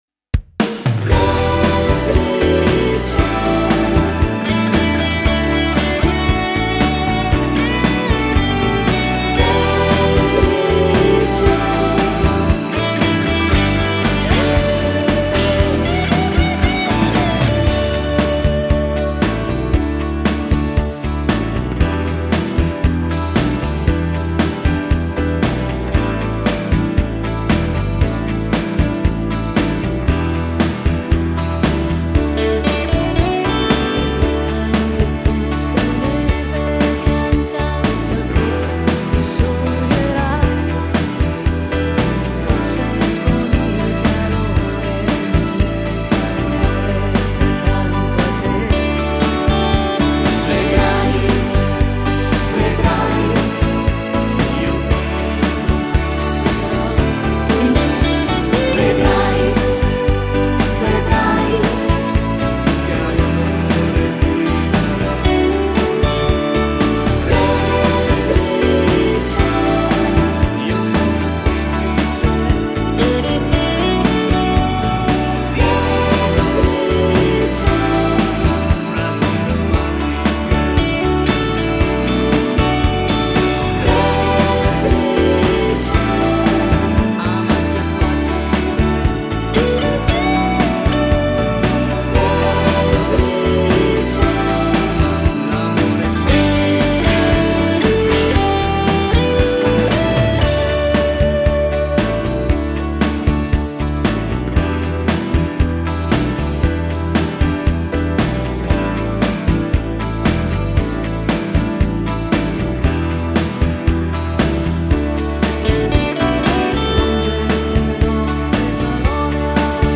Genere: Moderato
Scarica la Base Mp3 (3,45 MB)